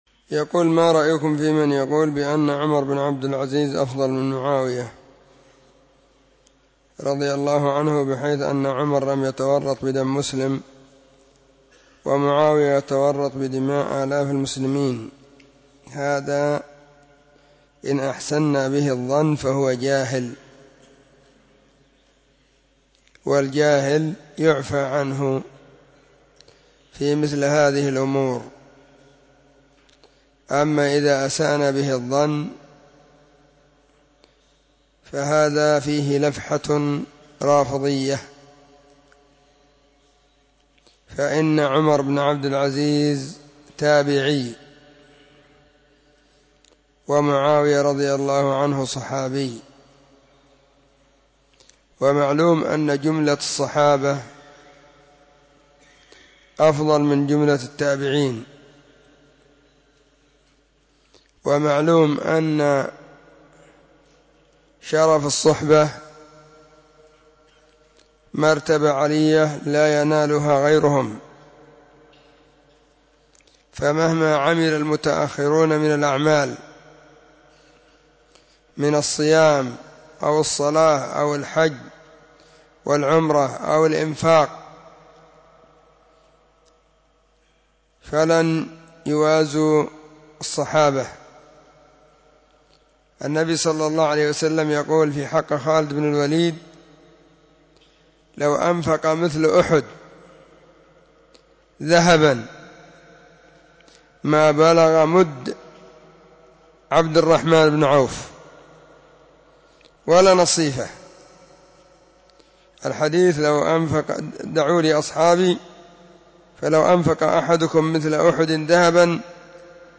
🔹 سلسلة الفتاوى الصوتية 🔸الاربعاء 13 /ذو القعدة/ 1442 هجرية.